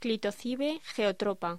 Locución: Clitocibe geotropa